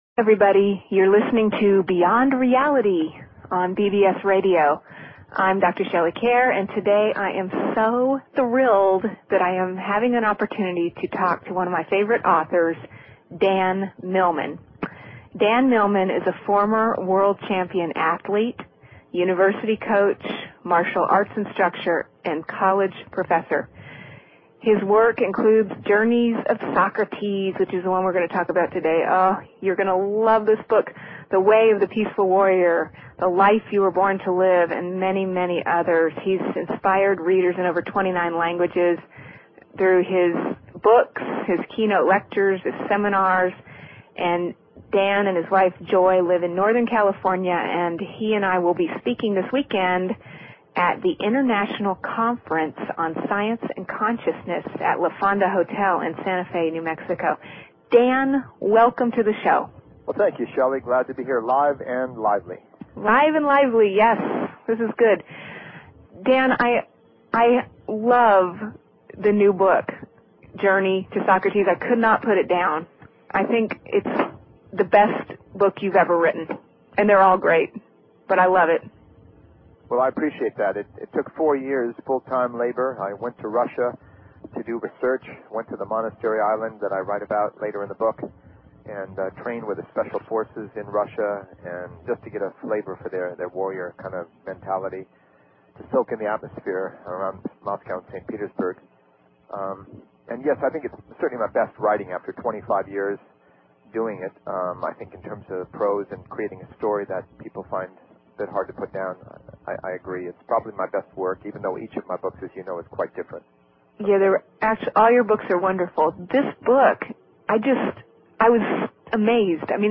Talk Show Episode, Audio Podcast, Beyond_Reality and Courtesy of BBS Radio on , show guests , about , categorized as
Interview with Dan Millman, author, professor, martial arts expert